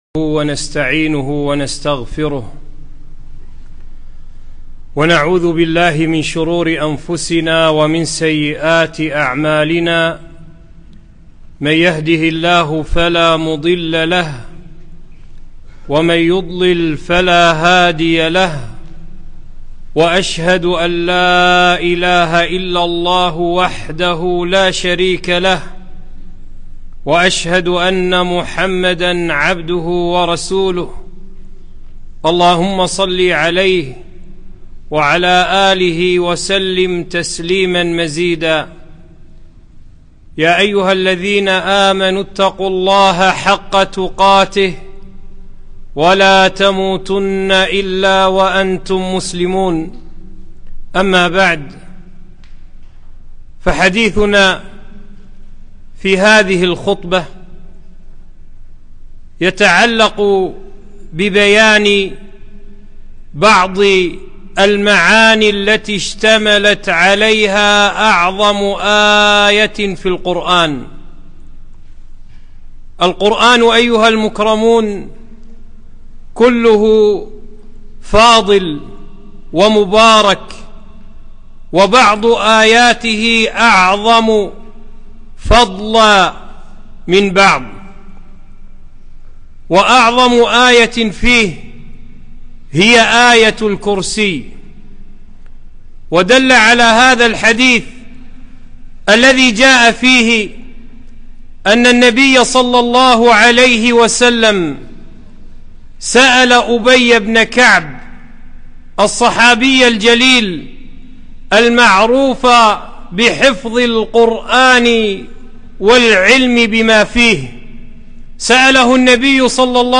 خطبة - بعض المعاني التي اشتملت عليها آية الكرسي - دروس الكويت